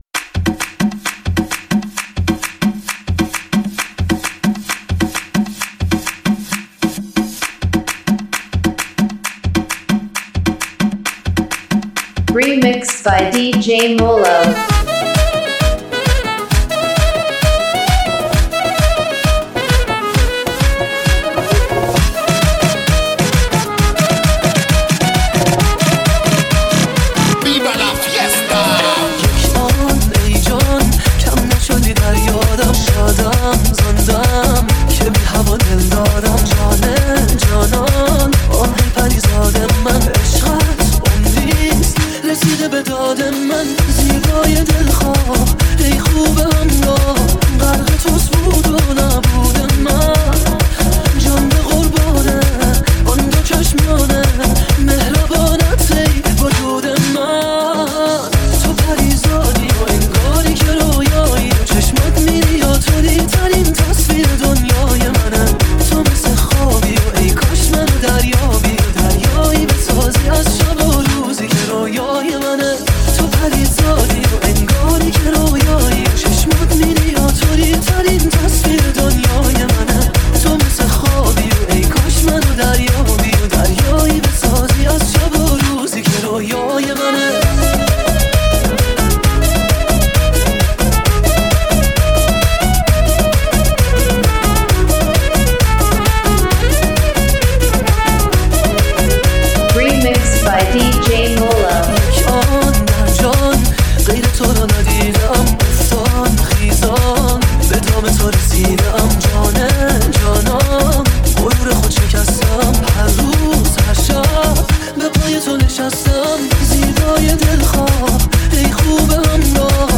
ریمیکس اول